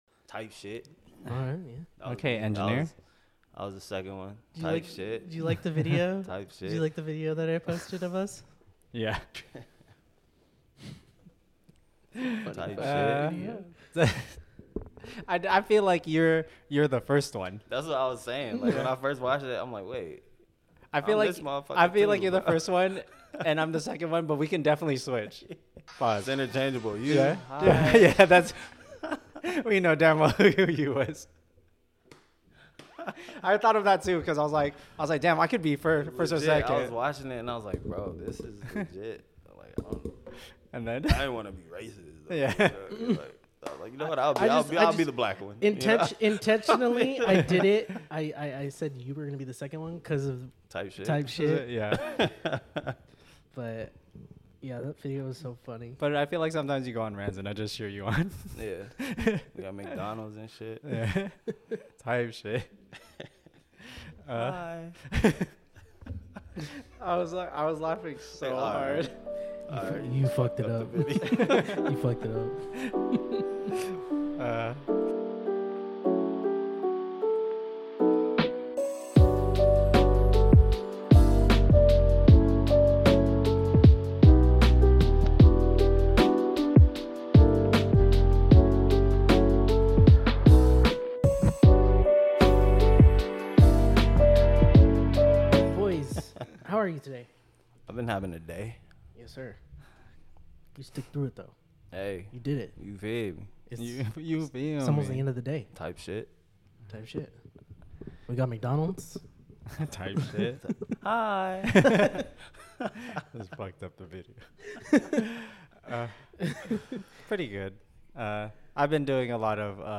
Apologies the audio is really wonky this time around the levels need to be adjusted but its still an episode for the week!